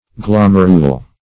Search Result for " glomerule" : Wordnet 3.0 NOUN (1) 1. a compacted or sessile cyme ; The Collaborative International Dictionary of English v.0.48: Glomerule \Glom"er*ule\, n. [Dim. fr. L. glomus ball.]